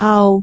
speech
syllable
pronunciation
aau6.wav